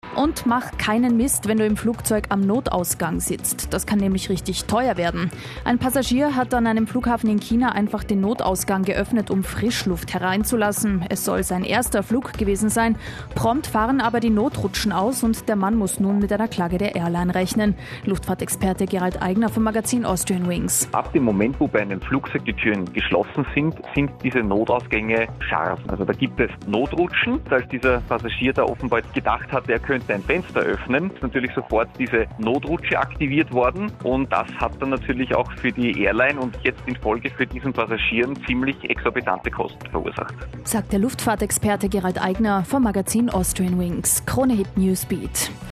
Kronehit_Interview_Zwischenfall-OverwingExit_China.MP3